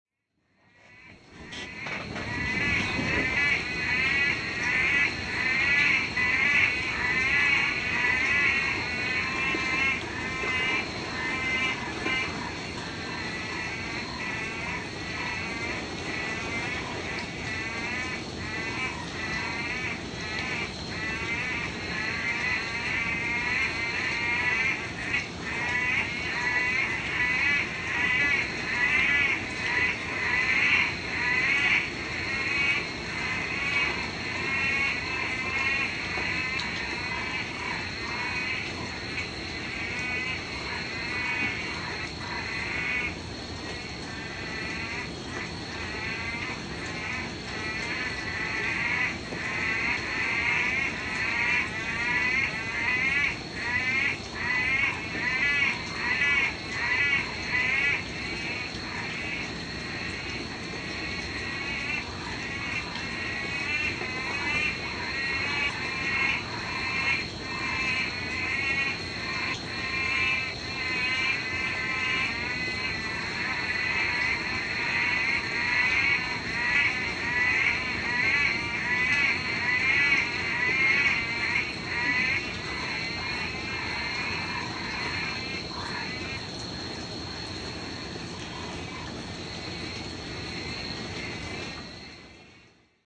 Loud Frogs Botão de Som